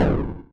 bomb.ogg